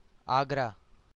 Agra (Hindi: Āgrā, pronounced [ˈaːɡɾɐː]